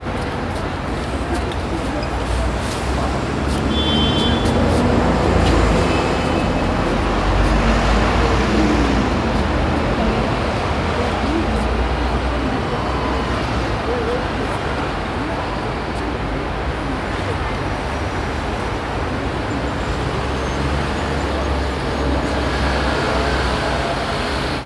На этой странице собраны звуки городского шума: гул машин, разговоры прохожих, сигналы светофоров и другие атмосферные звуки улиц.
Городской шум суеты